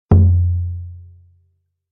surdo-4.mp3